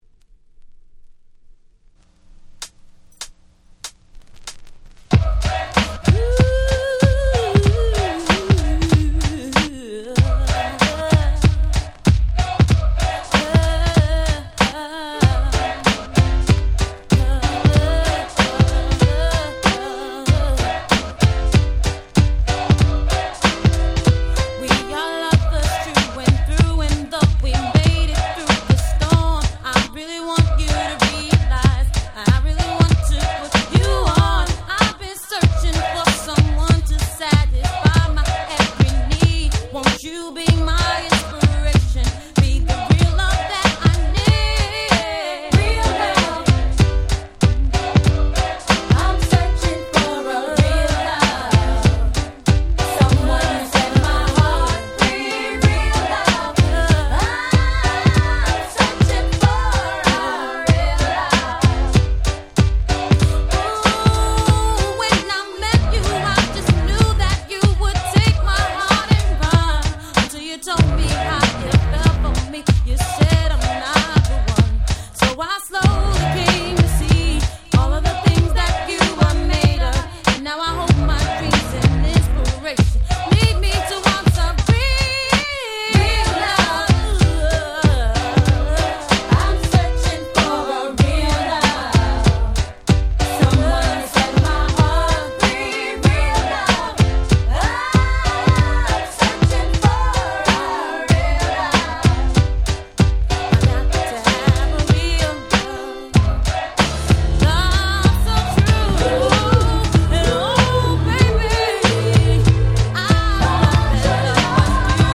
93' Super R&B Classics !!
90's キャッチー系